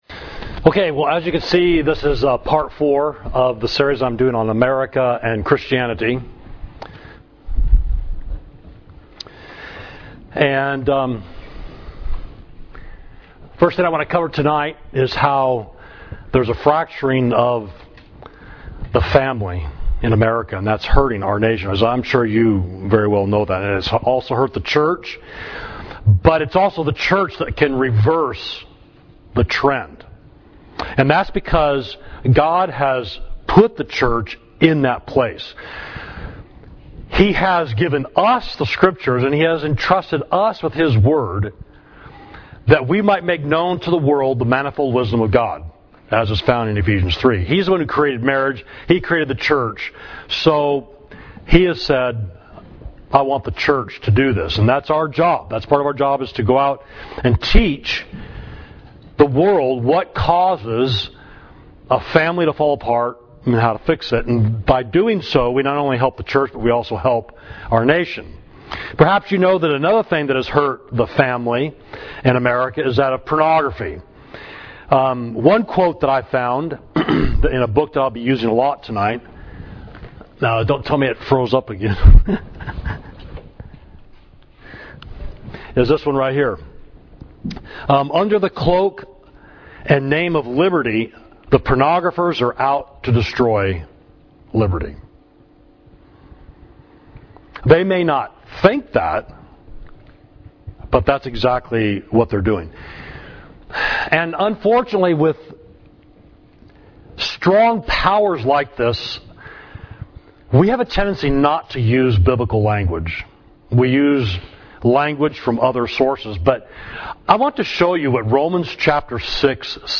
Sermon: America and Christianity – Part 4 – Savage Street Church of Christ